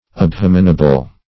abhominable - definition of abhominable - synonyms, pronunciation, spelling from Free Dictionary
Abhominable \Ab*hom"i*na*ble\, a.